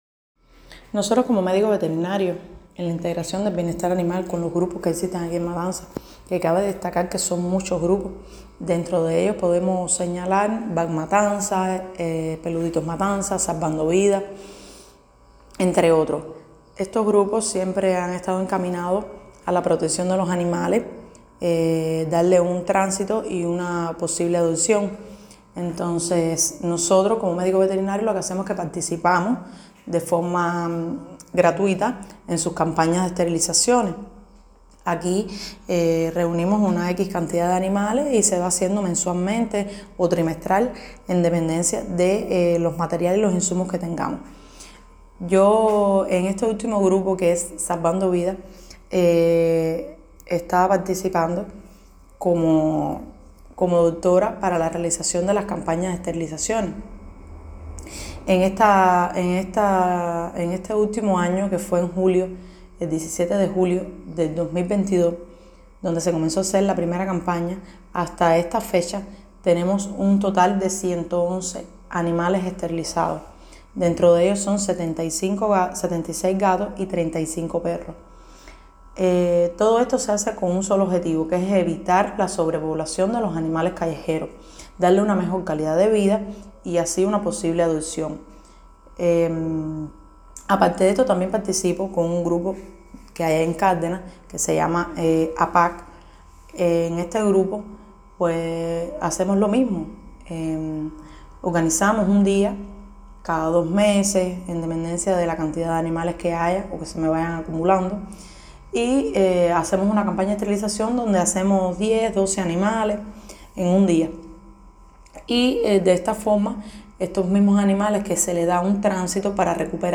Entrevista Matanzas